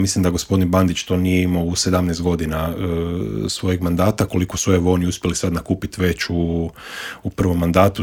U intervjuu Media servisa ugostili smo jedne od glasnijih kritičara Tomislava Tomaševića i Možemo - nezavisnu zastupnicu u Skupštini Grada Zagreba Dinu Dogan i vijećnika u Vijeću Gradske četvrti Črnomerec Vedrana Jerkovića s kojima smo prošli kroz gradske teme.